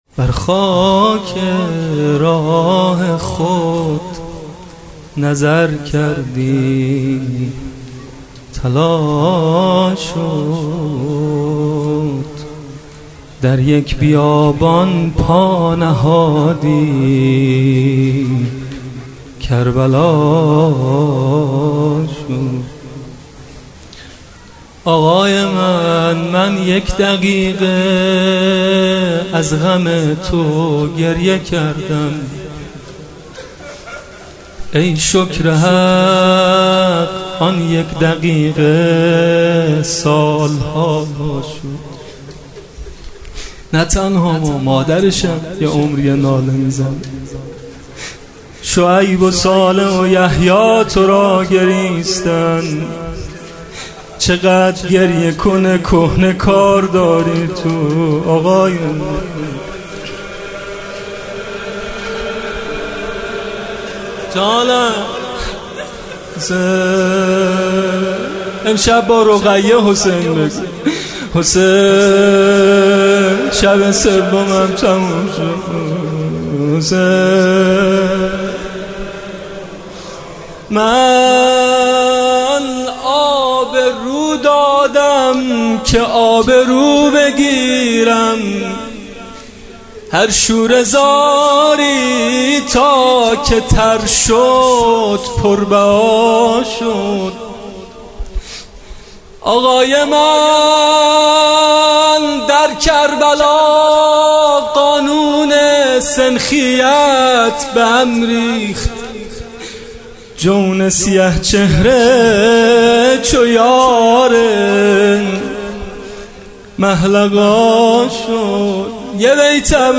گلچین مداحی شب سوم محرم ۹۸ با نوای میثم مطیعی
فایل صوتی گلچین مداحی شب سوم محرم ۹۸ با نوای مداح اهل بیت(ع) حاج میثم مطیعی در اینجا قابل دریافت است.
تهران- الکوثر:   شب سوم محرم به نام حضرت رقیه (س) بود و مداحان  اهل بیت (ع) نوحه‌های خود را به این حضرت اختصاص دادند.